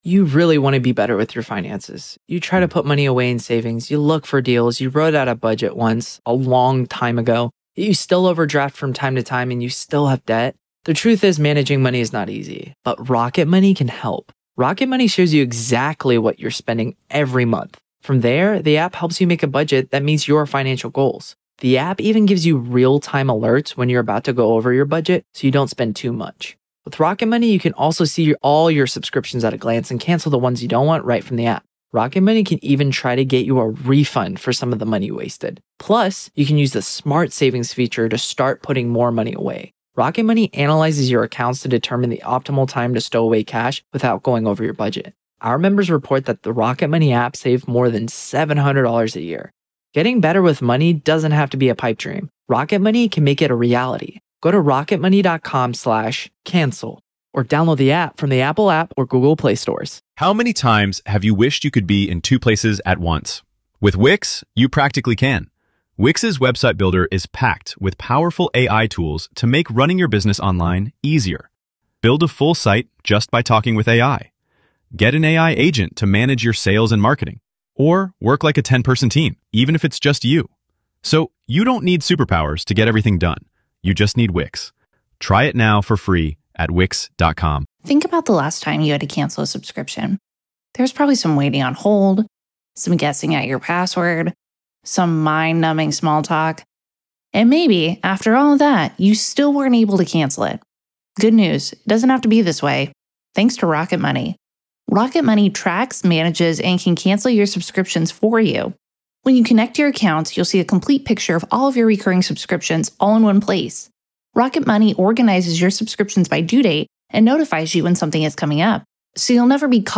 Each episode navigates through these stories, illuminating their details with factual reporting, expert commentary, and engaging conversation.